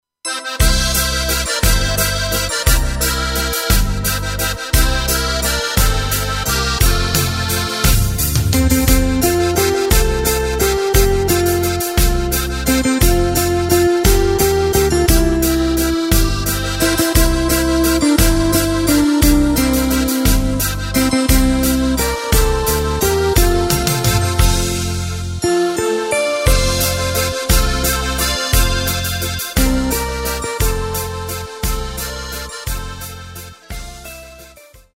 Takt:          3/4
Tempo:         174.00
Tonart:            F
Walzer aus dem Jahr 2009!